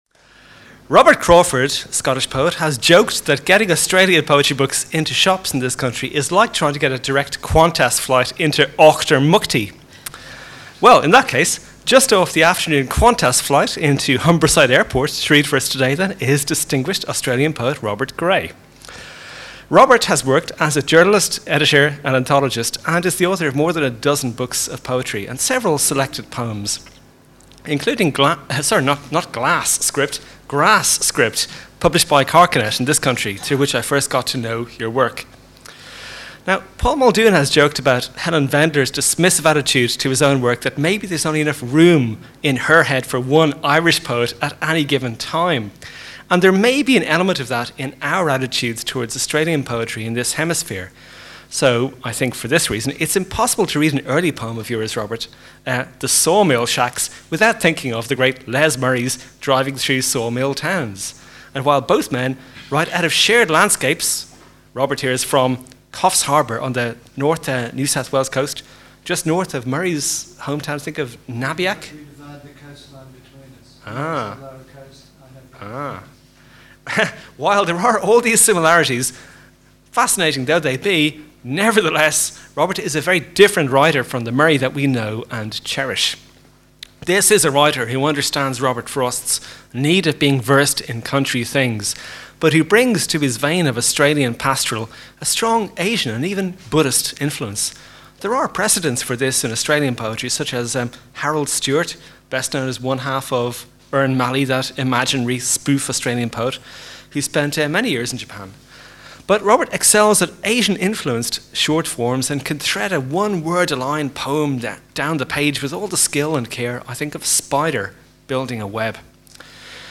One captures Ireland, the other Australia - a unique and lively gathering as two wondrous poets meet.